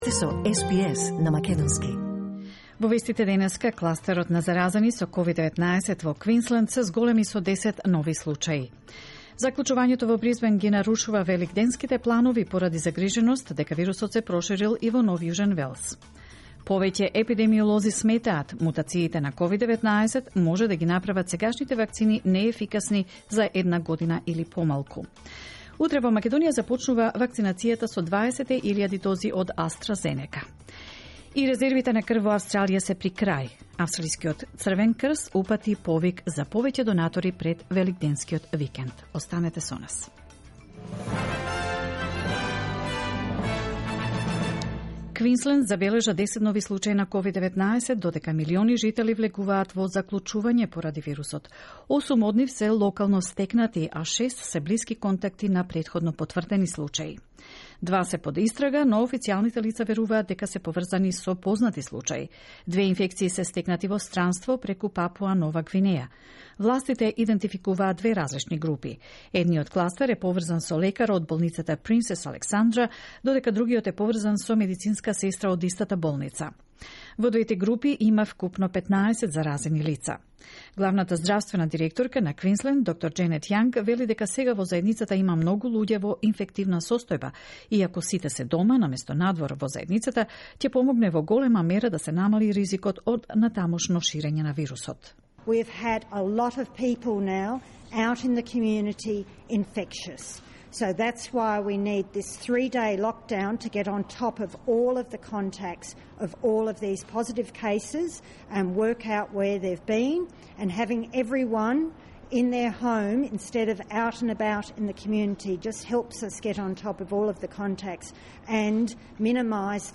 SBS News in Macedonian 30 March 2021